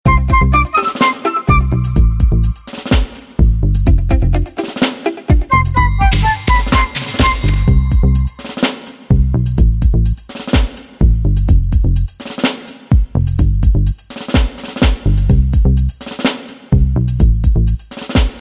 - Mp3 Instrumental Song Track